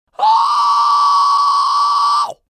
Fry Scream simple sifflet - Whistle Scream
17Whistle-Scream-simple.mp3